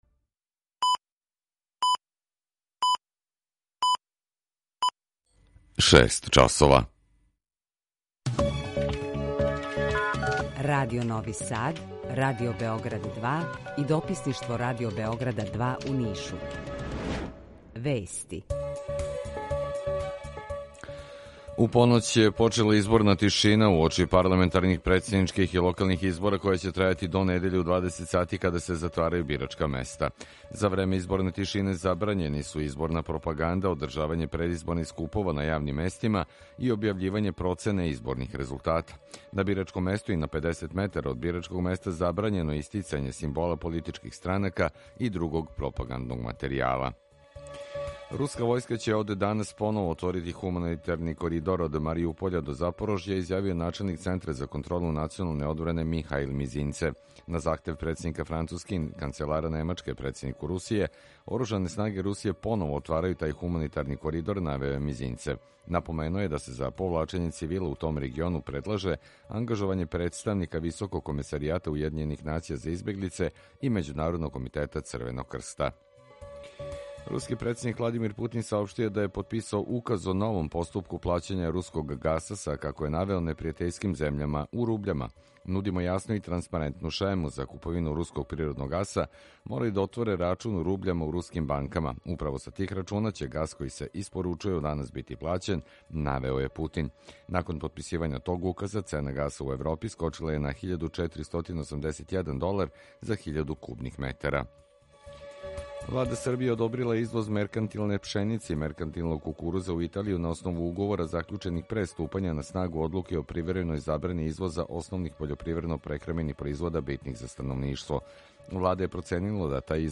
Емисију реализујемо са Радијом Републике Српске из Бањалуке и Радијом Нови Сад
Јутарњи програм из три студија
У два сата, ту је и добра музика, другачија у односу на остале радио-станице.